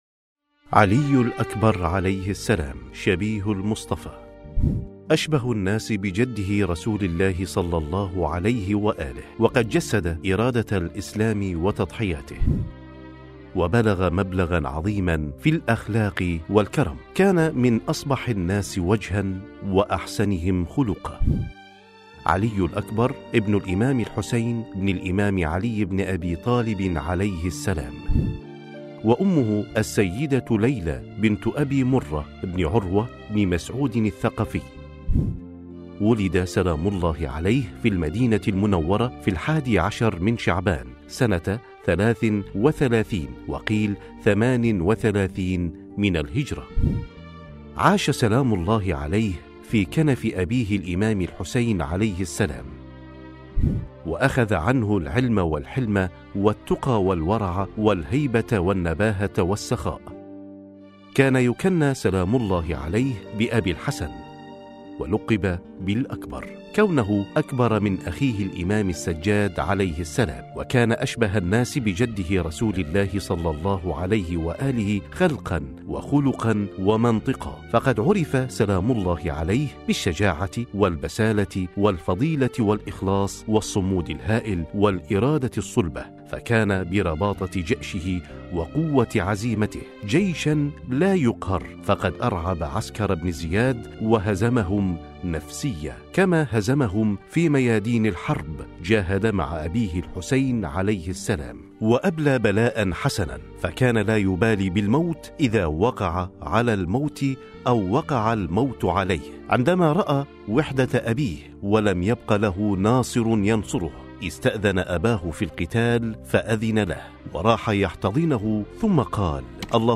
ترجمة صوتية